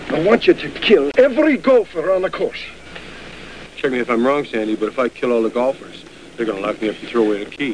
1 channel
gopher.mp3